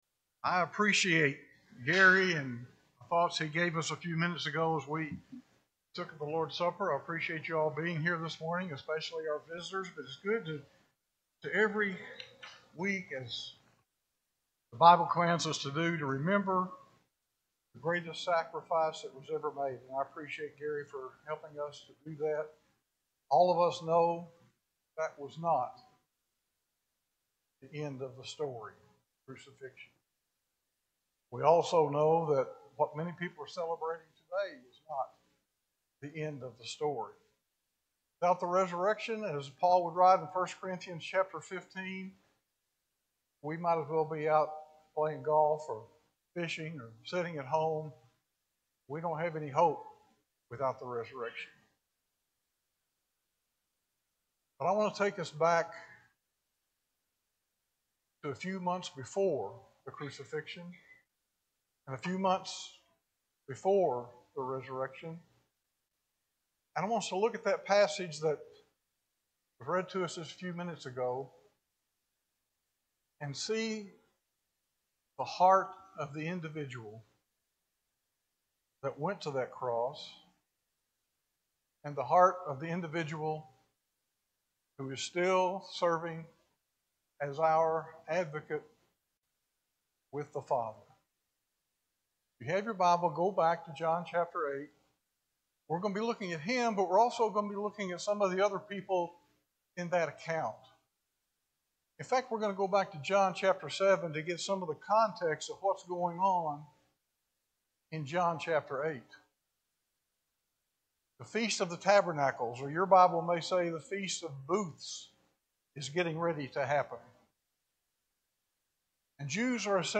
4-5-26-Sunday-AM-Sermon.mp3